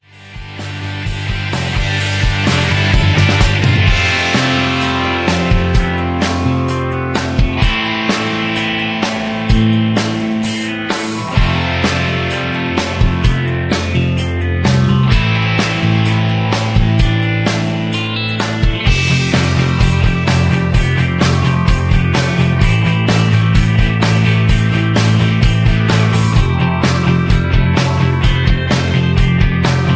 (Key-Am) Karaoke MP3 Backing Tracks
Just Plain & Simply "GREAT MUSIC" (No Lyrics).